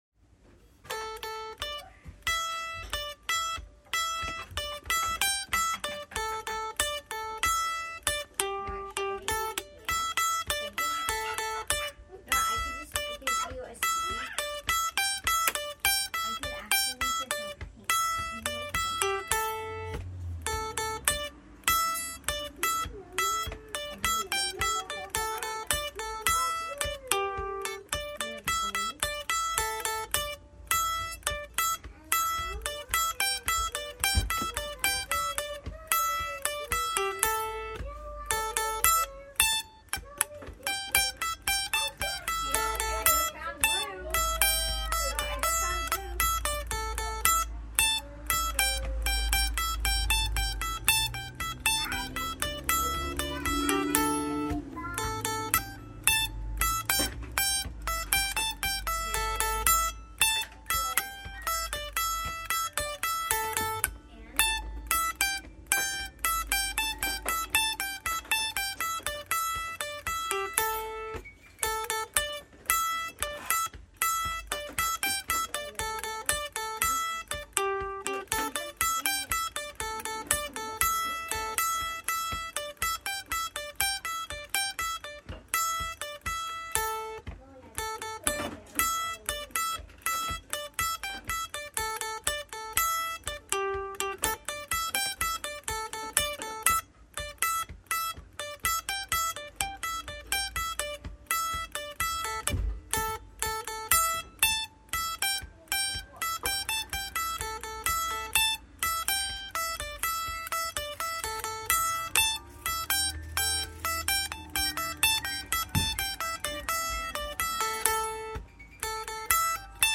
Here's some playing it slowly, on mandolin (electric but not plugged in) and piano, to give a sense of how I'm imagining it:
(mandolin, mp3)